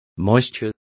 Complete with pronunciation of the translation of moisture.